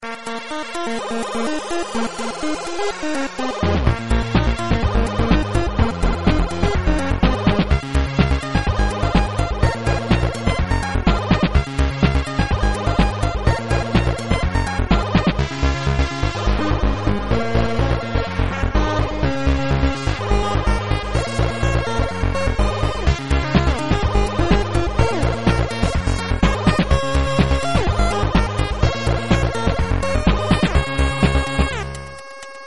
Chiptune song introduction